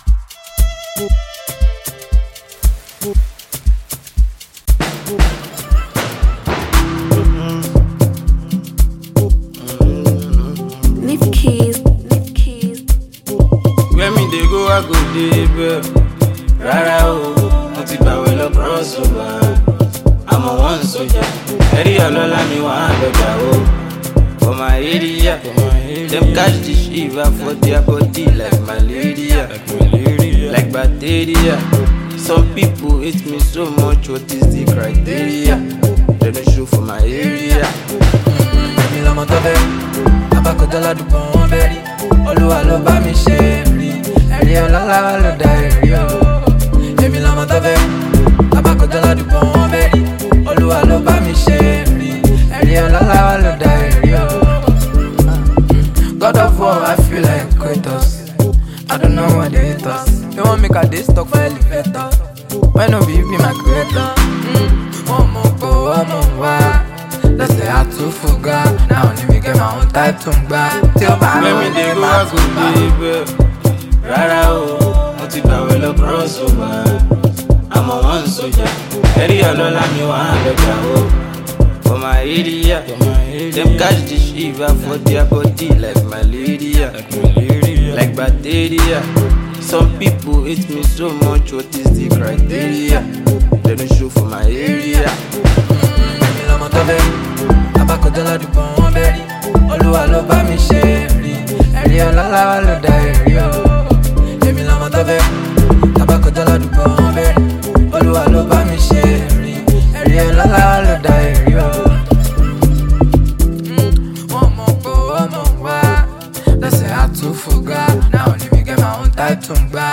Nigerian / African Music
African Music Genre: Afrobeats Released